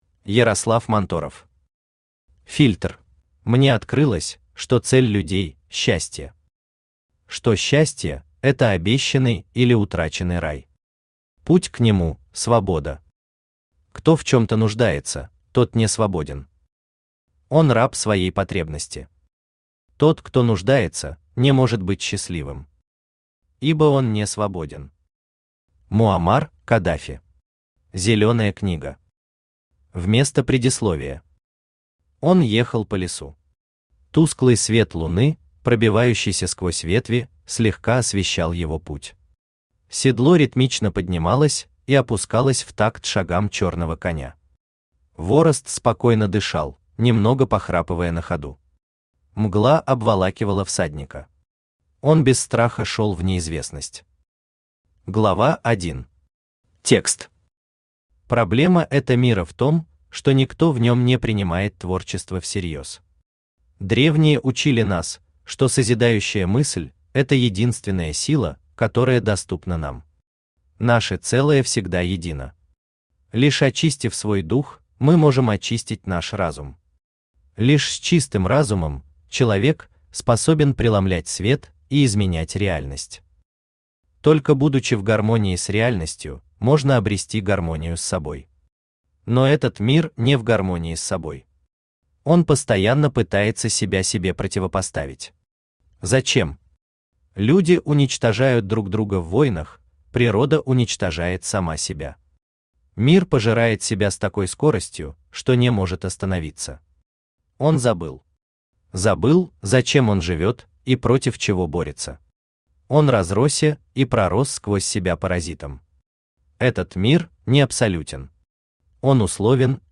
Аудиокнига Фильтр | Библиотека аудиокниг
Aудиокнига Фильтр Автор Ярослав Манторов Читает аудиокнигу Авточтец ЛитРес.